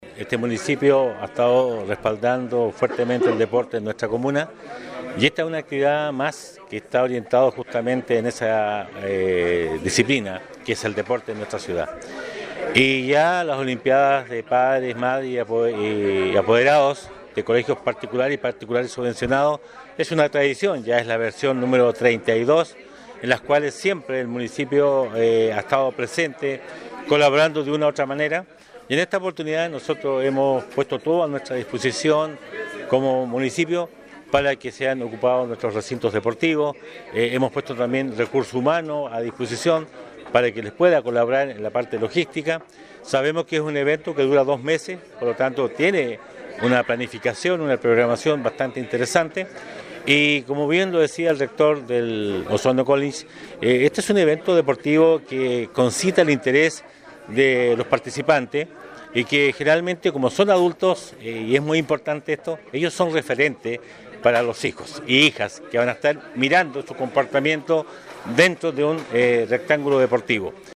Así lo destacó el alcalde Emeterio Carrillo, al subrayar que este evento forma parte de la tradición de Osorno y que, por lo mismo, el municipio apoya su realización, facilitando los recintos que sean necesarios con el fin de motivar la actividad física, la recreación y la unidad entre los establecimientos educacionales.